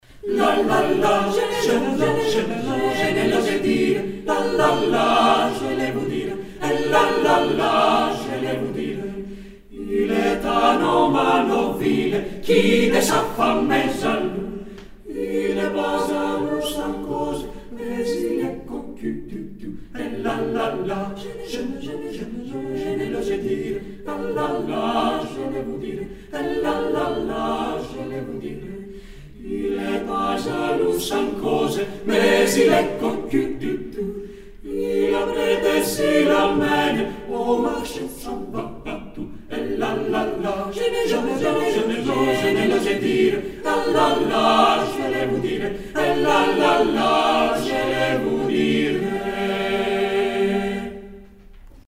Με ισοστάθμιση, αντήχηση και στερεοφωνία